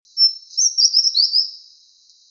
28-1-阿里山2011麟胸鷦鷯1.mp3
臺灣鷦眉 Pnoepyga formosana
嘉義縣 阿里山
錄音環境 闊葉林
鳥叫